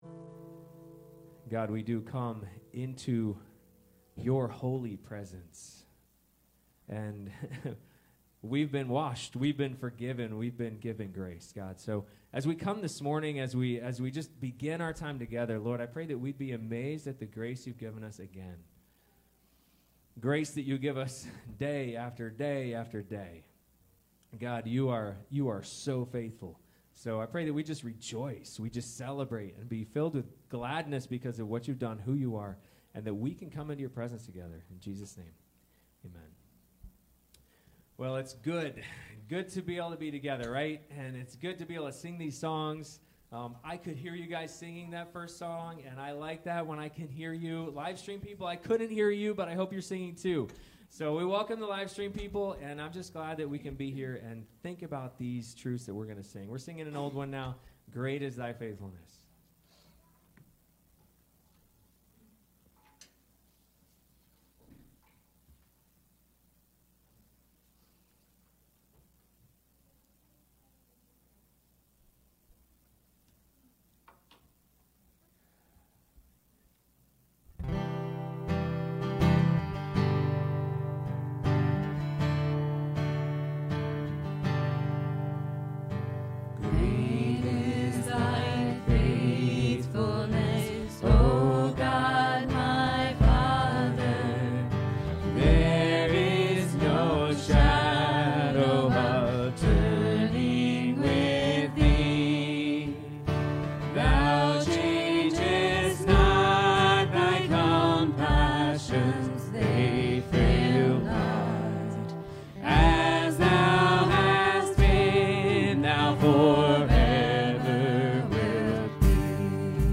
Sermon Downloads
Service Type: Sunday Morning